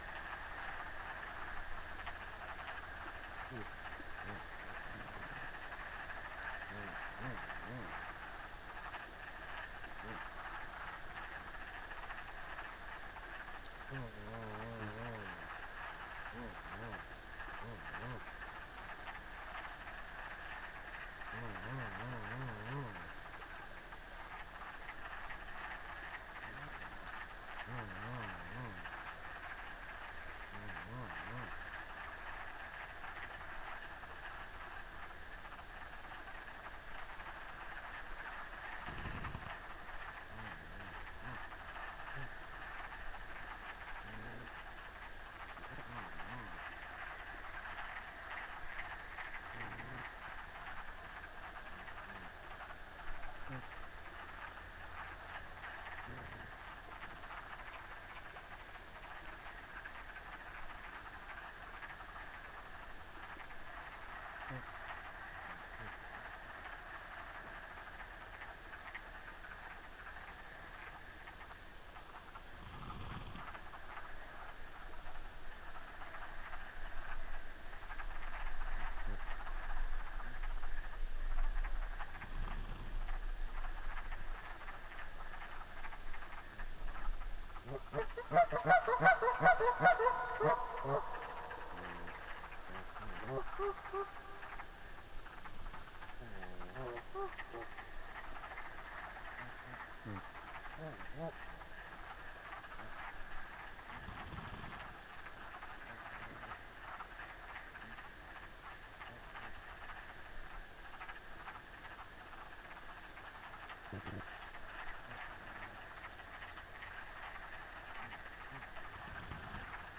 Late night sounds at this site in Kootenay National Park on April 19, 2010